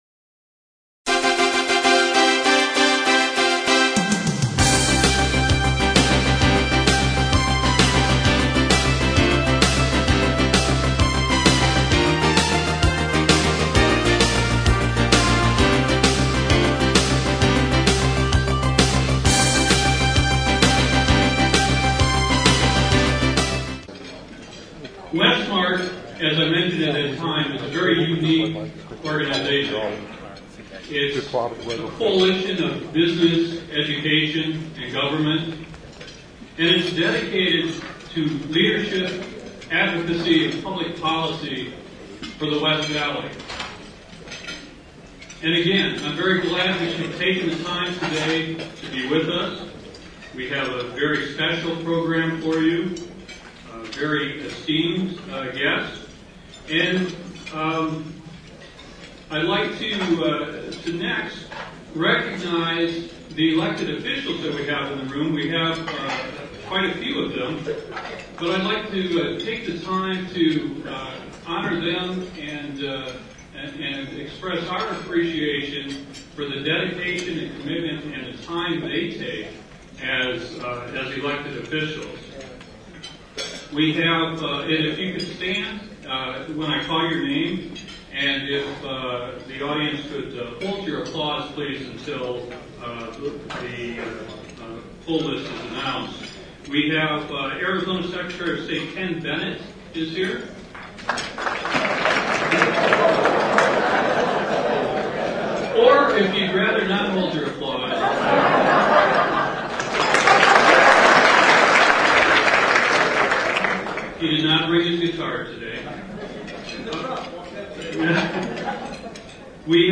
WATCH VIDEO OF THE MEETING AND HEAR THE GOVERNOR'S SPEECH >>CLICK HERE!<<